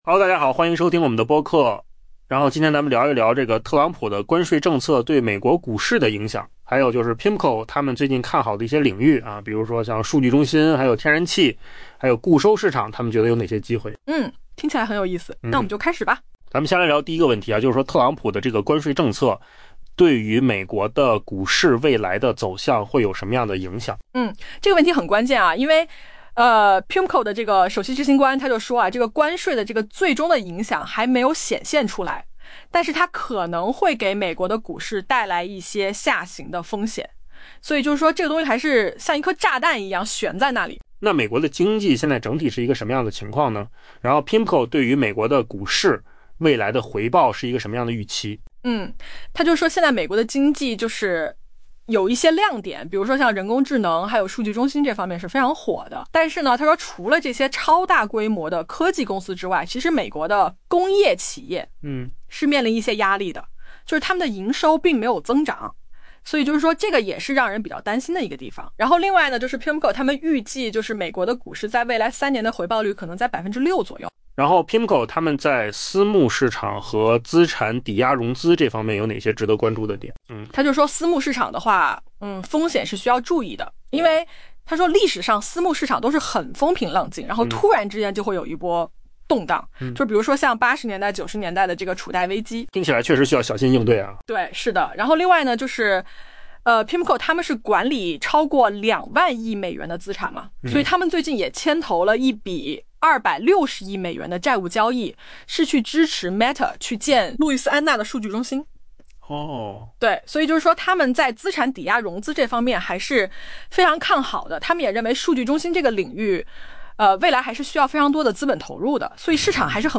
AI 播客：换个方式听新闻 下载 mp3 音频由扣子空间生成 太平洋投资管理公司 （PIMCO） 首席执行官伊曼纽尔·罗曼 （Emmanuel Roman） 表示，特朗普的关税政策效果尚未显现，其最终走向可能会拖累美国股市前景。